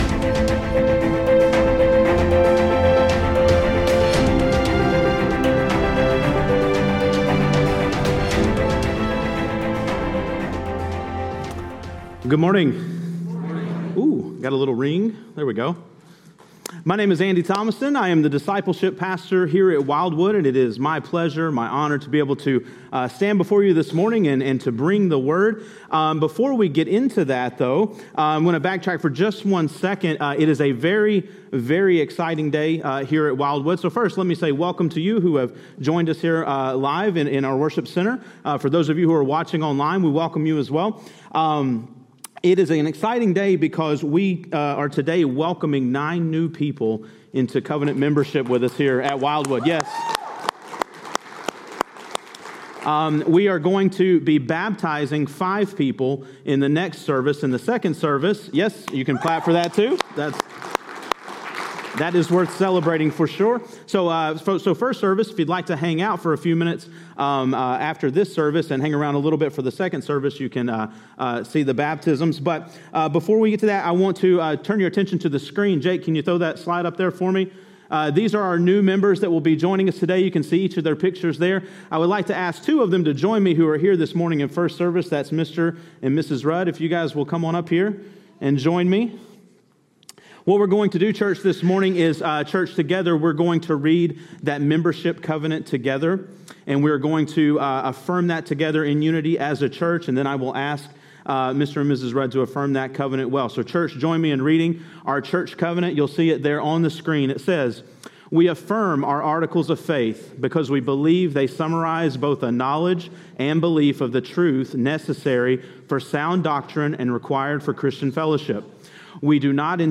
A message from the series "Vision 20/20."